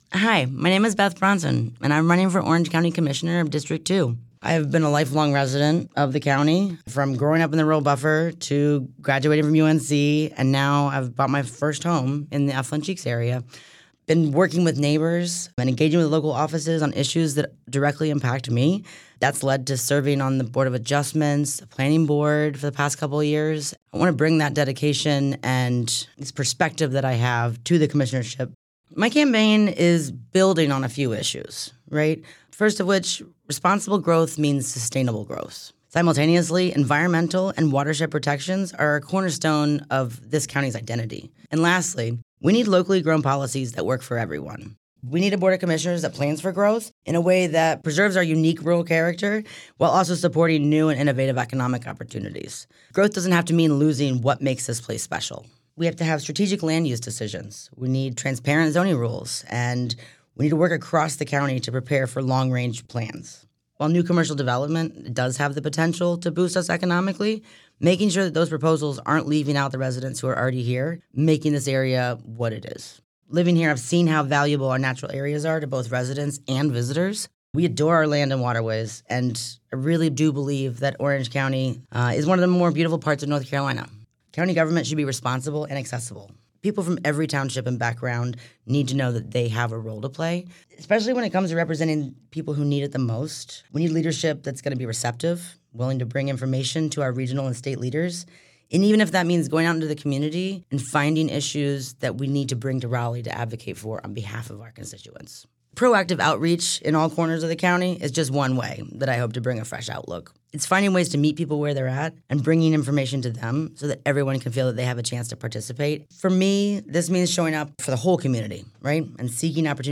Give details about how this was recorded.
97.9 The Hill spoke with each Democrat candidate, asking these questions that are reflected in the recorded responses: